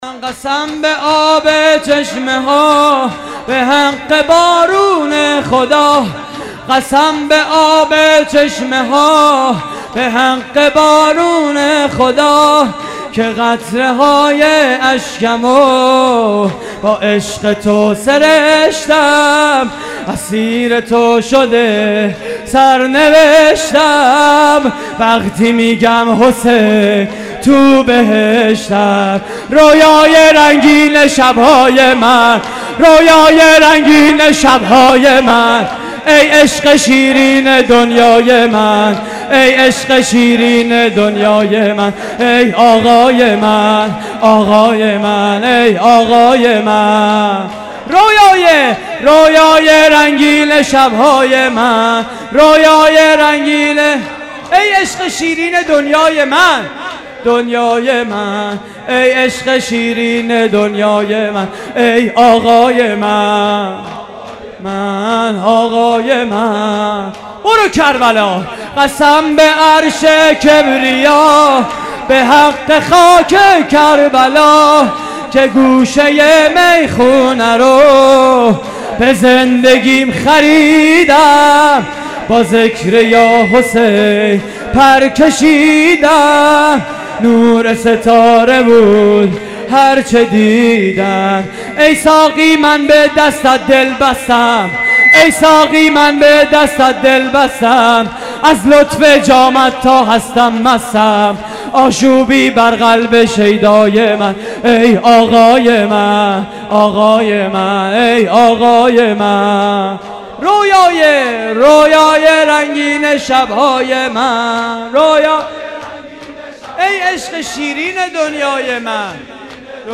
مراسم عزاداری شب ششم ماه محرم / هیئت کانون دانش آموزی امام حسن مجتبی (ع) - نازی‌آباد؛ 20 آذر 89
شور: رؤیای رنگین شب‌های من